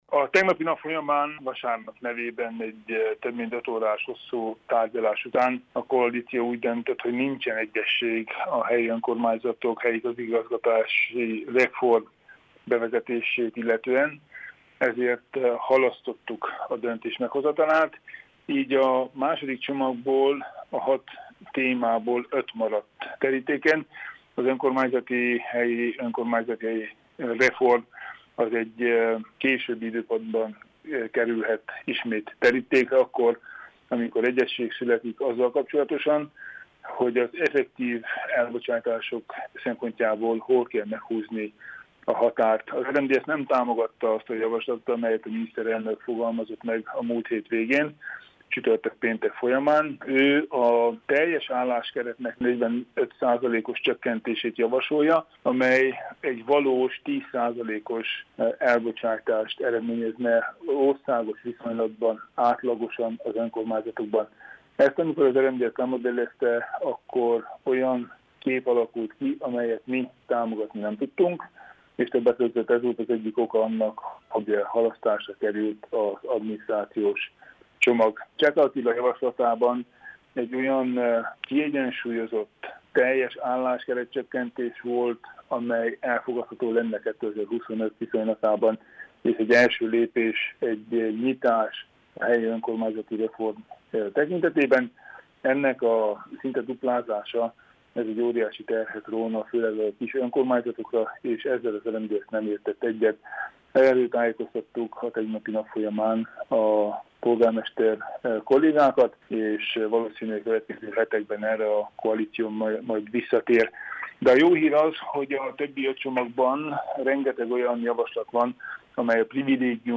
Tánczos barna szenátort kérdezet